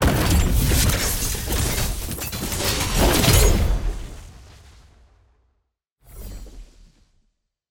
sfx-tier-wings-promotion-to-master.ogg